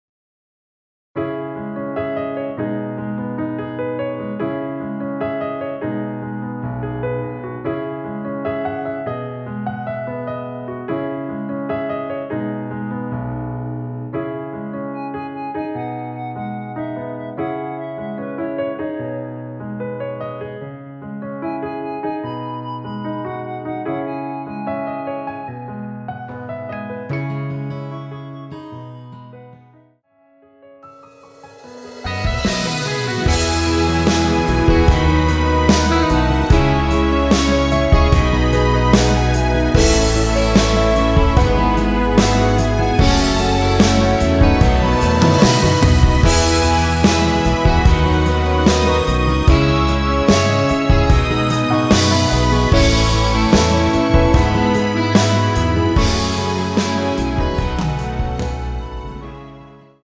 원키에서 (+2)올린 멜로디 포함된 MR 입니다.(미리듣기 참조)
C#m
앞부분30초, 뒷부분30초씩 편집해서 올려 드리고 있습니다.
중간에 음이 끈어지고 다시 나오는 이유는